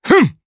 DarkKasel-Vox_Attack3_jp.wav